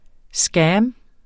Udtale [ ˈsgæːm ]